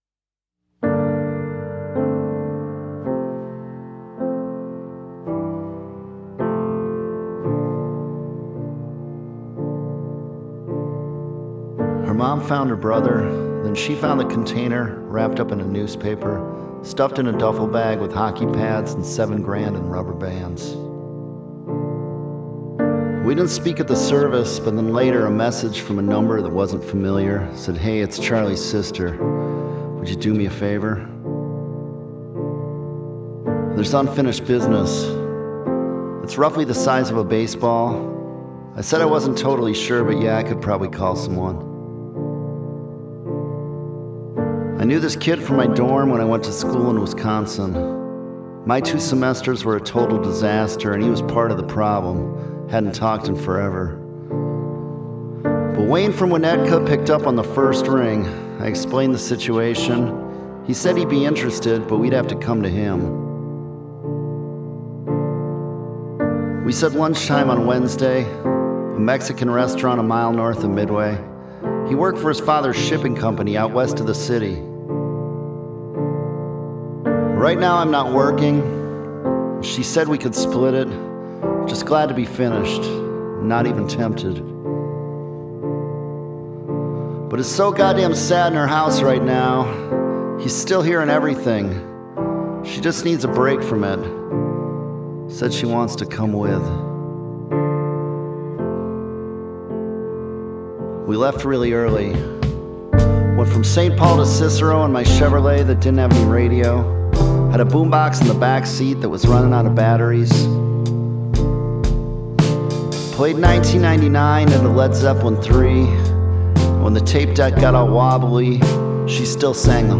The music is mostly uplifting
a spoken-word absolute stunner.